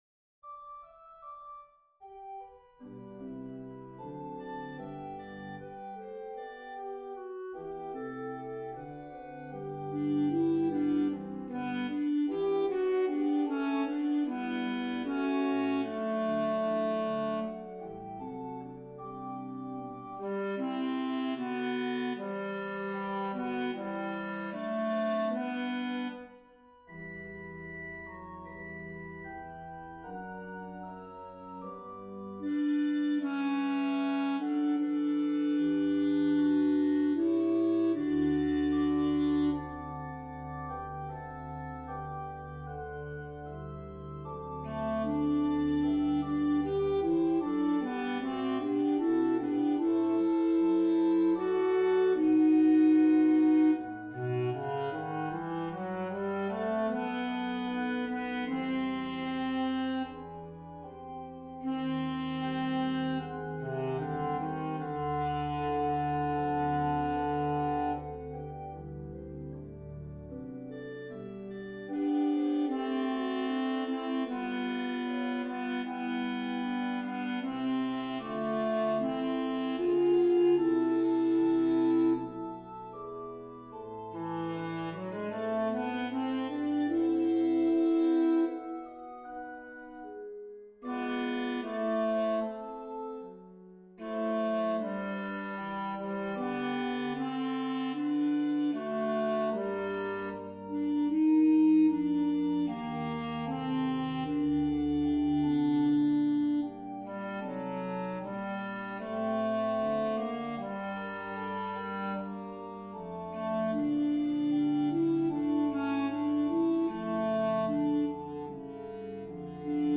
How beauteous are their feet RR Tenor